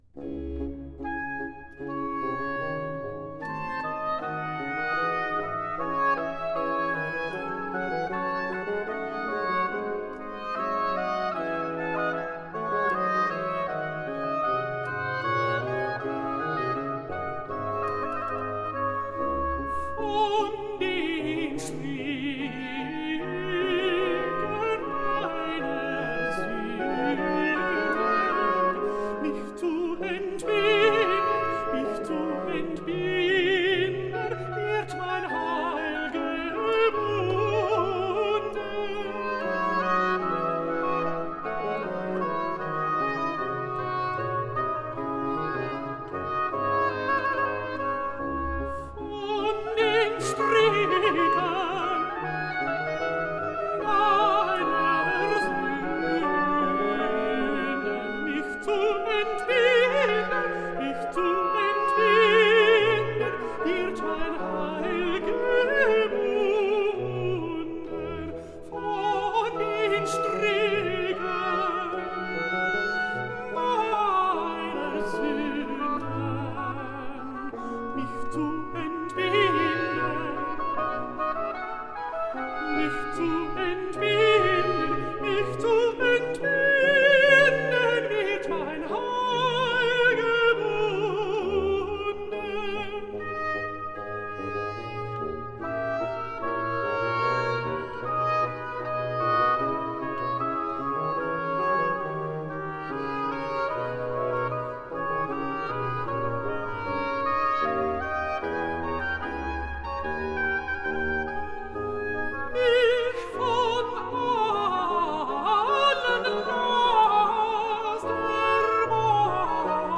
Aria (Alto)